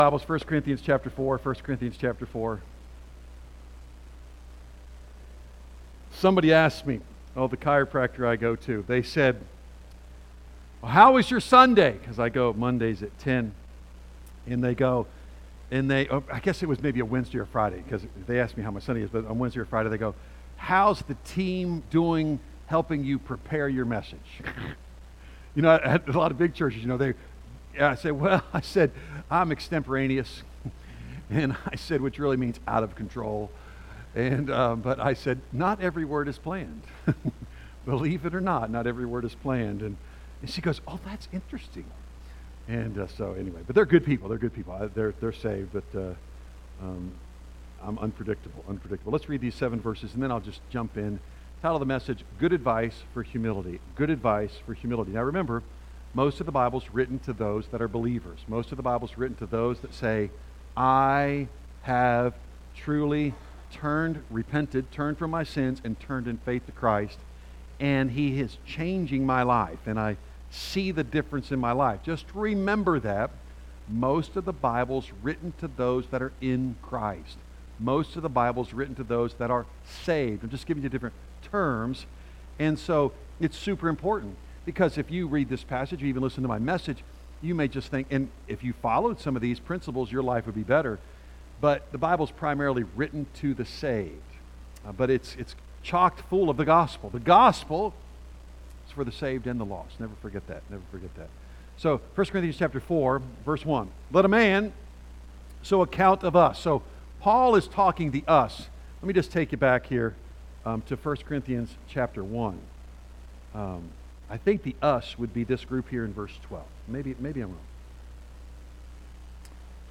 A message from the series "1 Corinthians."